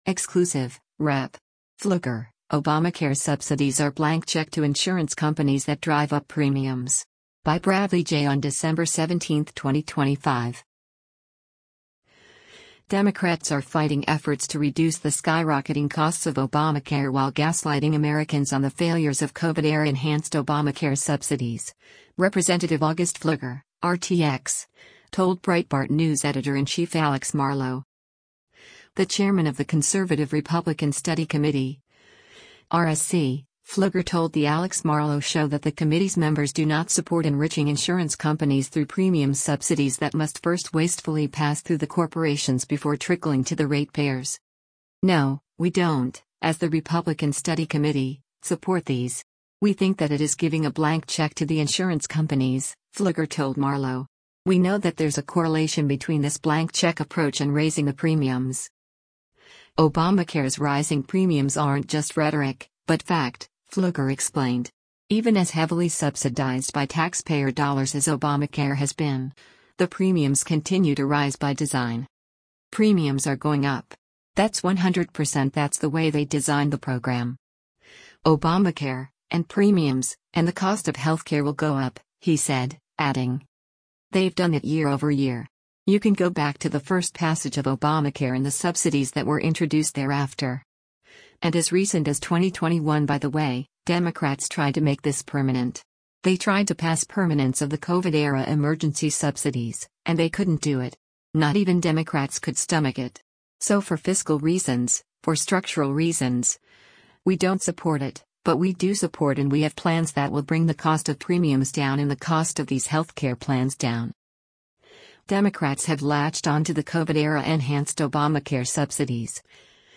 is a weekday podcast produced by Breitbart News and Salem Podcast Network.